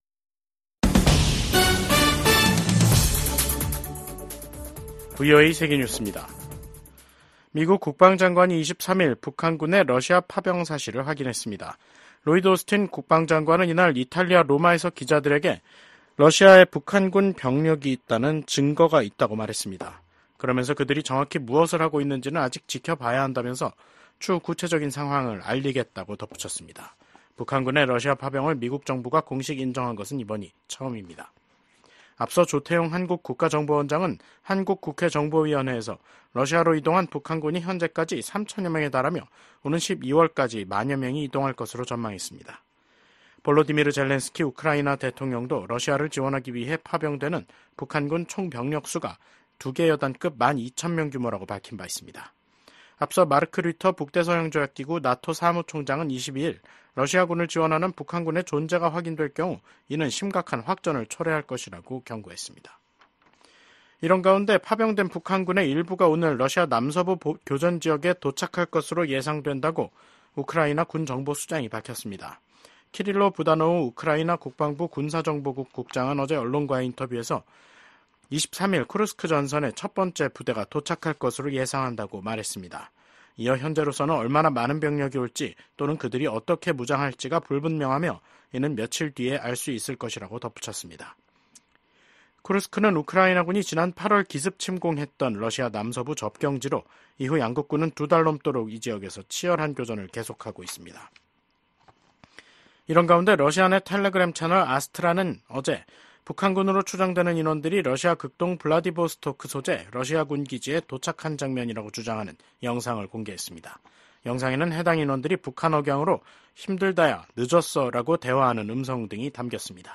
VOA 한국어 간판 뉴스 프로그램 '뉴스 투데이', 2024년 10월 23일 3부 방송입니다. 국무부는 한국이 우크라이나에 무기지원을 검토할 수 있다고 밝힌 데 대해 모든 국가의 지원을 환영한다는 입장을 밝혔습니다. 미국 하원의원들이 북한이 러시아를 지원하기 위해 특수부대를 파병했다는 보도에 깊은 우려를 표했습니다.